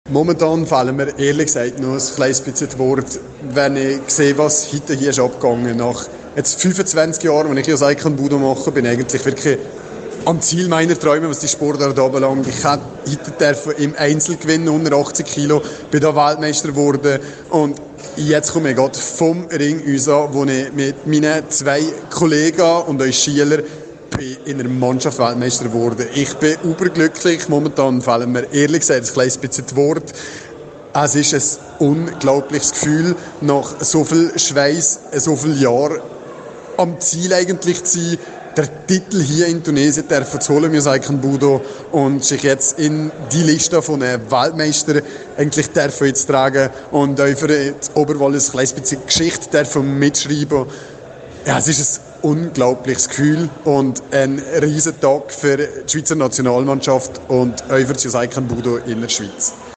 Zum Interview von RRO